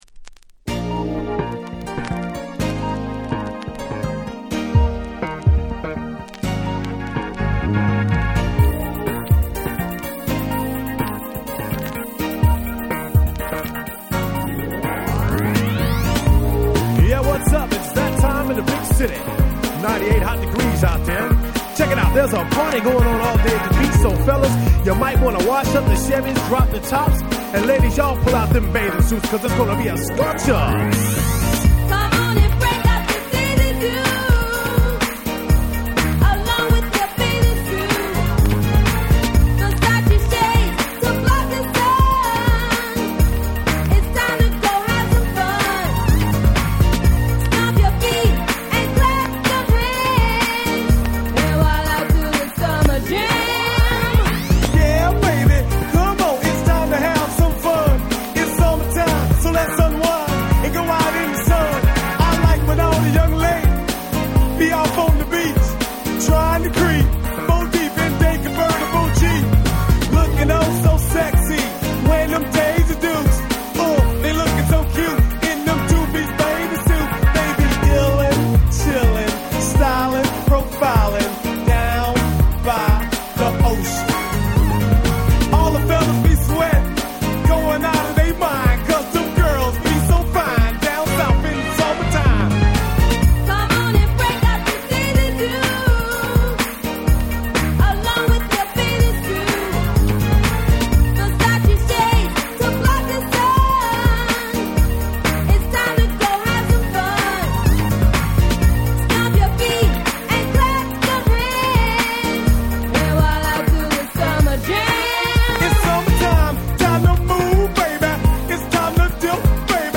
97' Smash Hit Hip Hop / Miami Bass !!
Bass系にしてはBPM若干緩めのSmooth Danceチューン！！